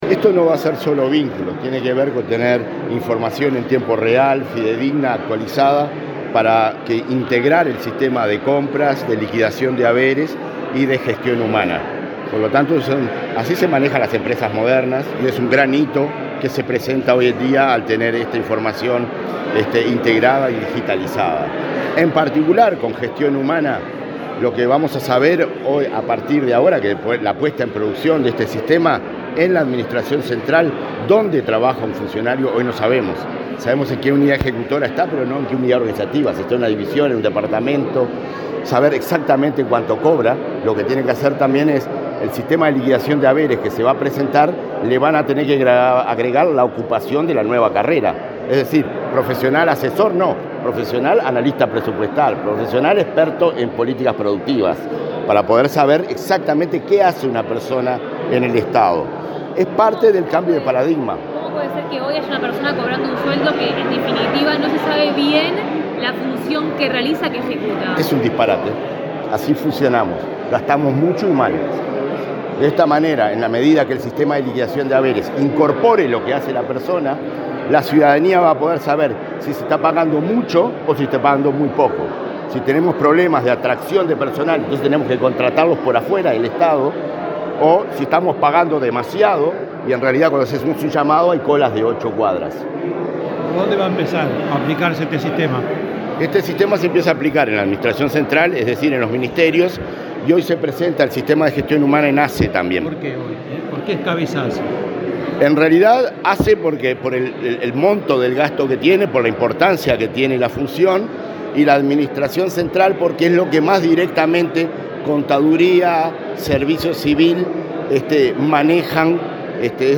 Declaraciones del director del ONSC, Conrado Ramos
Antes, dialogó con la prensa.